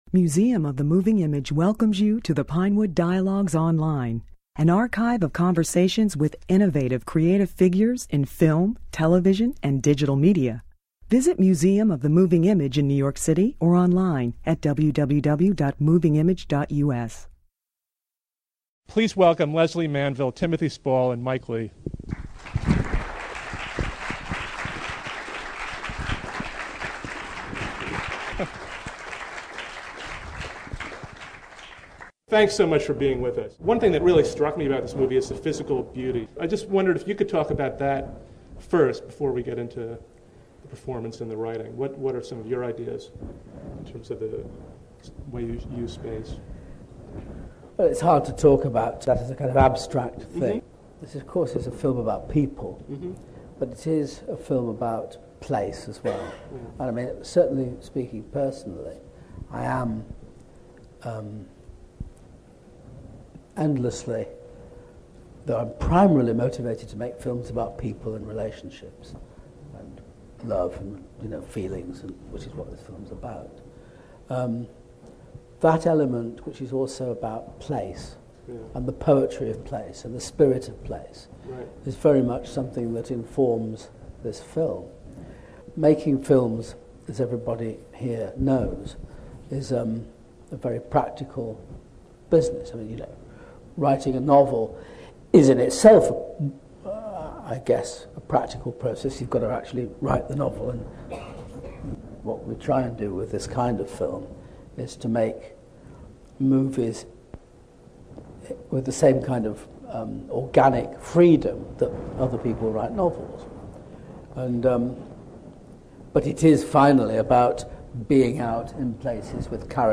In this discussion just before the film's New York premiere, Leigh, Spall, and Manville elaborate on their creative process.